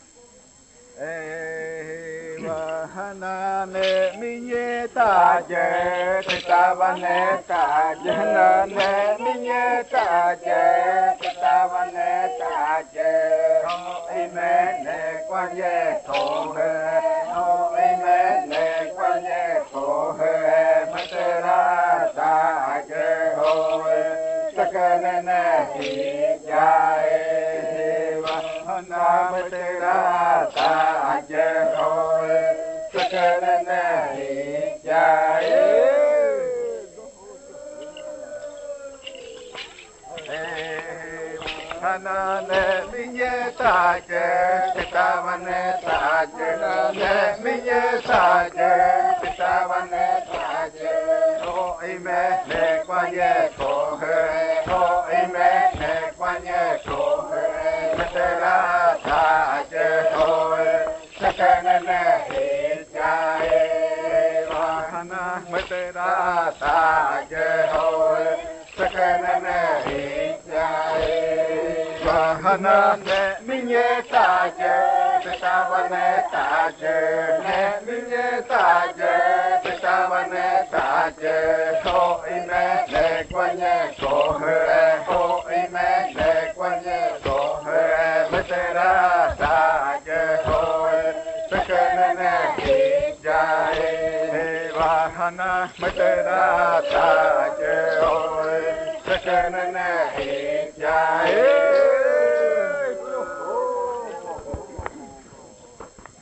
38. Baile de nombramiento. Canto n°10
Décimo canto del baile de Pichojpa Majtsi
en casete y en el año de 1990